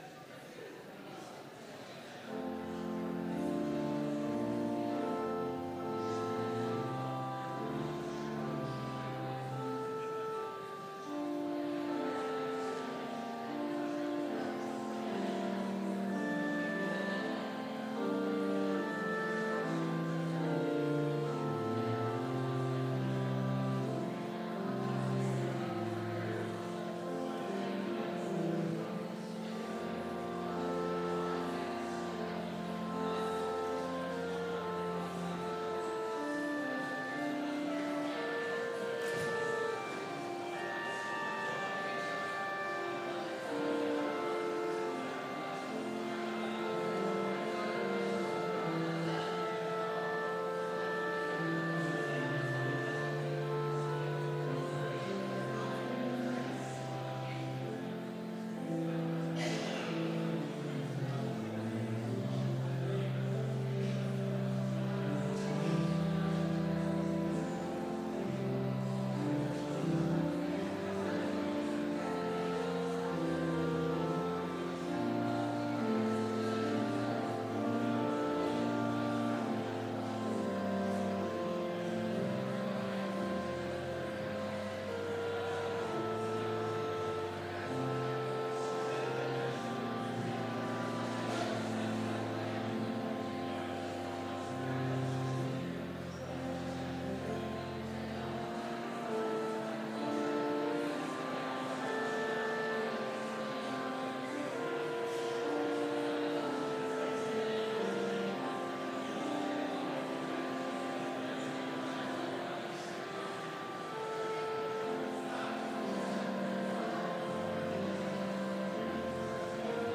Complete service audio for Chapel - September 11, 2019
Complete Service